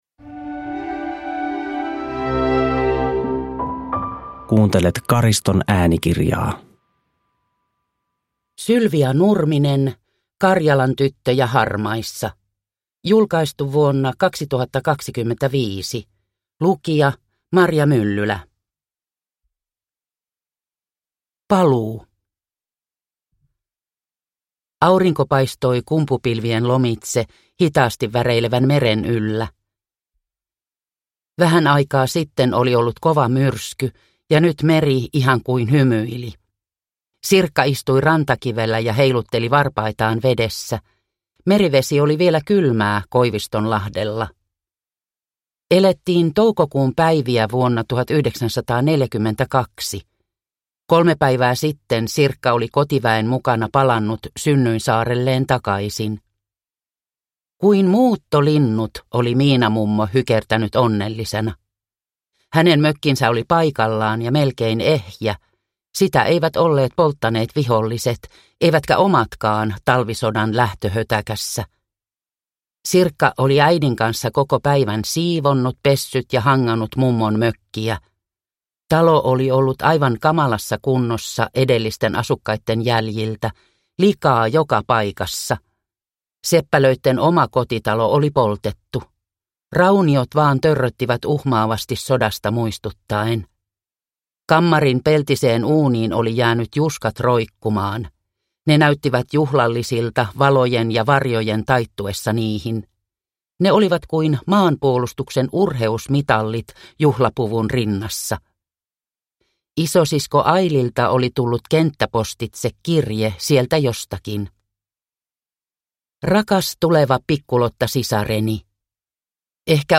Karjalan tyttöjä harmaissa (ljudbok) av Sylvia Nurminen